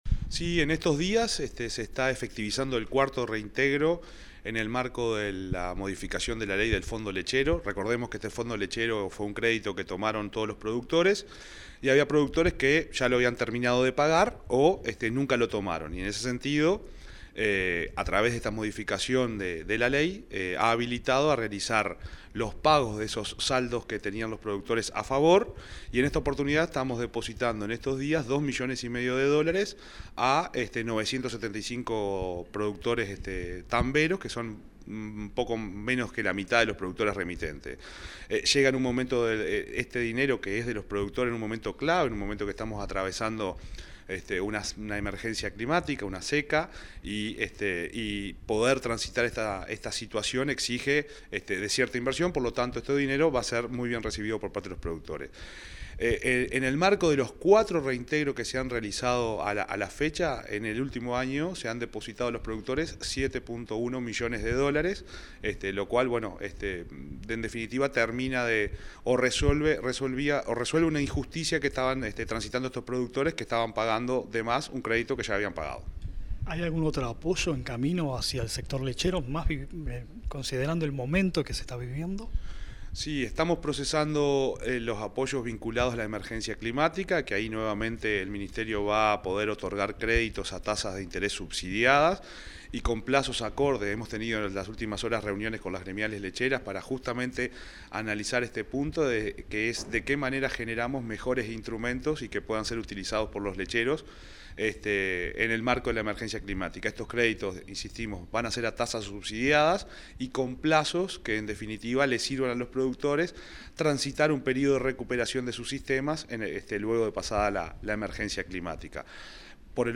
Entrevista al subsecretario de Ganadería, Juan Ignacio Buffa